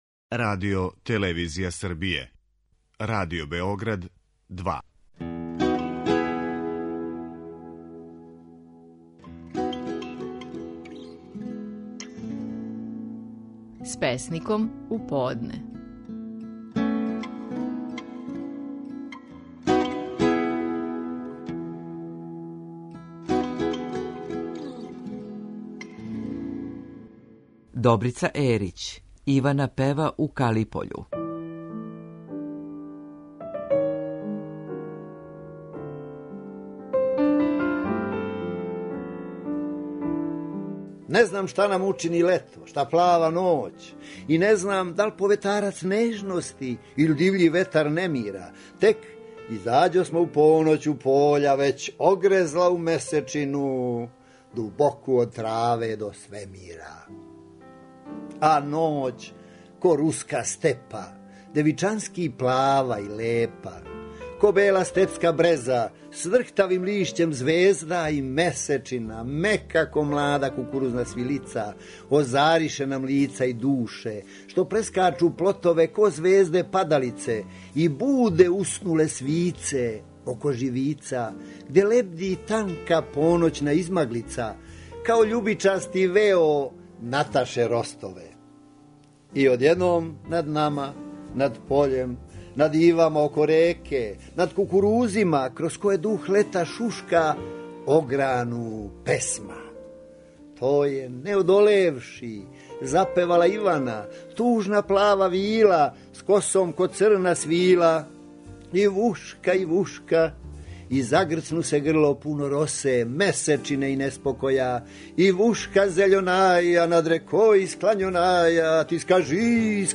Стихови наших најпознатијих песника, у интерпретацији аутора.
У данашњој емисији слушамо како је стихове своје песме „Ивана пева у Калипољу" говорио Добрица Ерић.